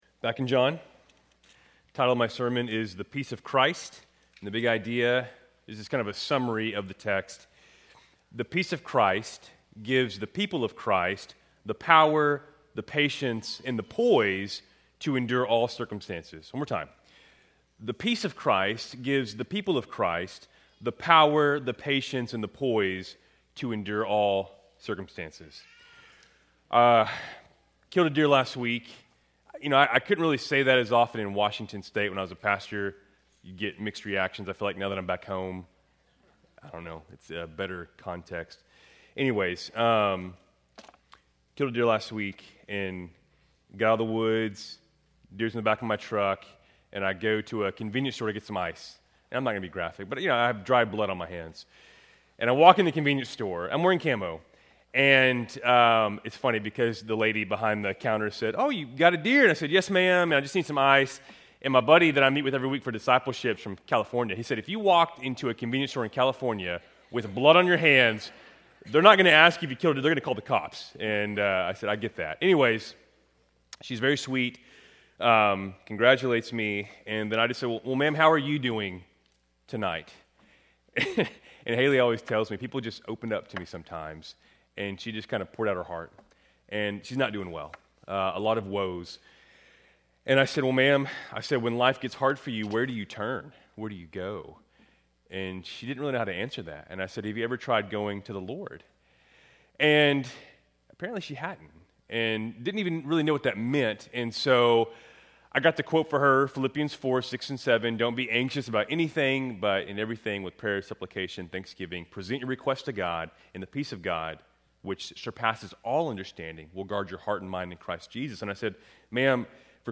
Keltys Worship Service, November 2, 2025